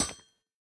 Minecraft Version Minecraft Version 1.21.5 Latest Release | Latest Snapshot 1.21.5 / assets / minecraft / sounds / block / copper_grate / step1.ogg Compare With Compare With Latest Release | Latest Snapshot
step1.ogg